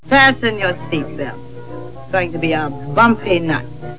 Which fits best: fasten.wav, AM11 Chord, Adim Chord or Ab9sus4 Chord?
fasten.wav